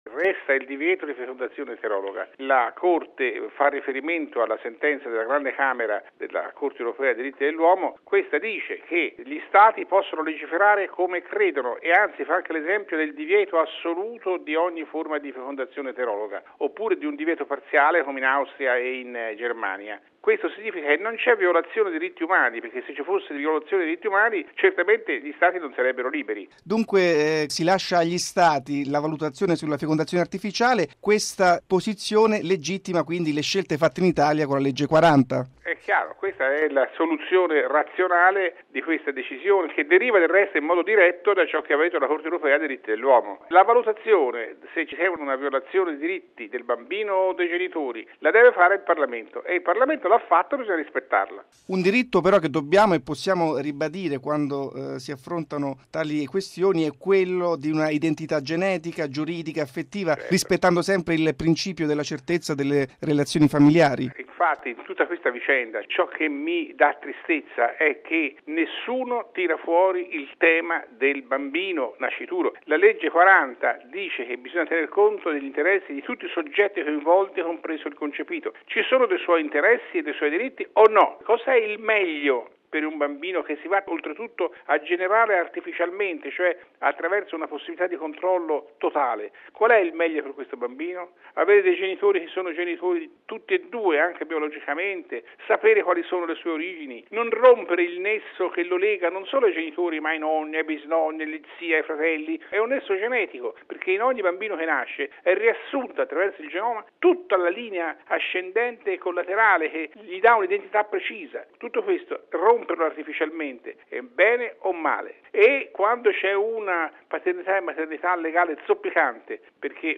Radiogiornale del 23/05/2012 - Radio Vaticana